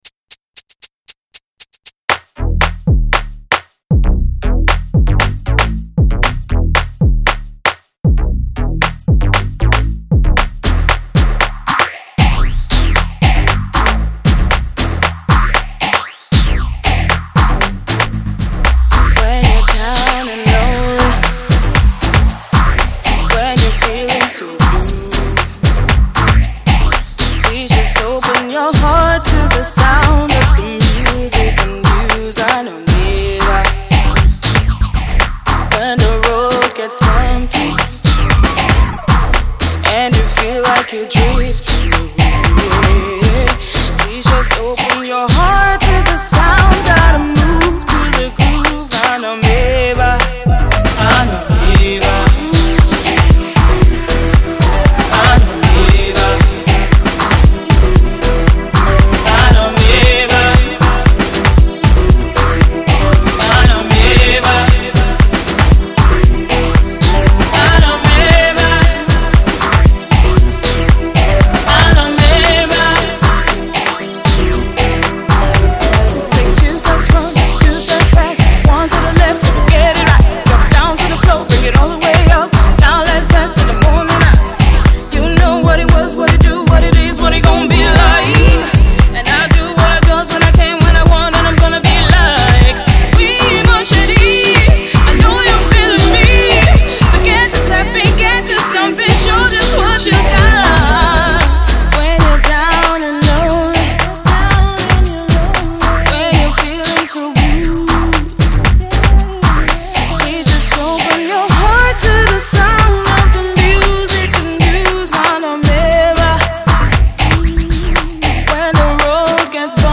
up-tempo Afro Pop jam that work on the dancefloor